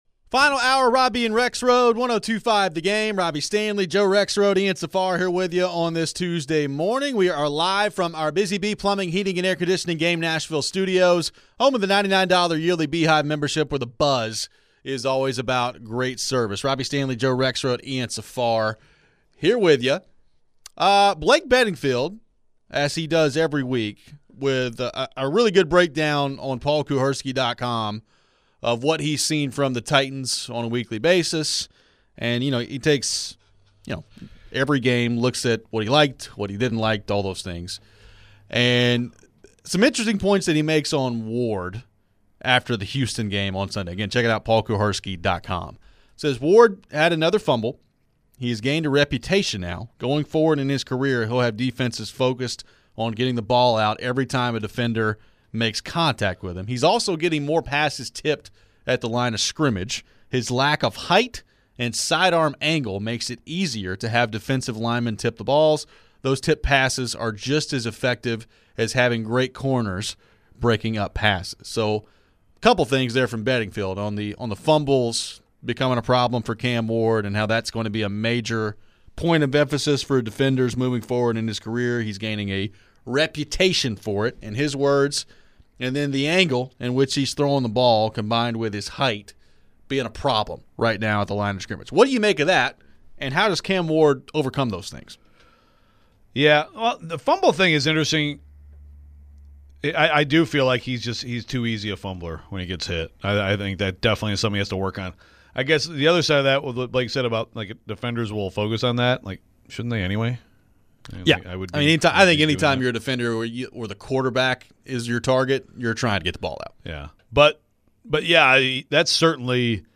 We return to the discussion on the Titans and how we are feeling about the progress of Cam Ward thus far. Is Ward's throwing motion a cause for concern with too much side-arm action? We take your phones and share more thoughts on the coaching search.